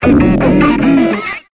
Cri de Grodrive dans Pokémon Épée et Bouclier.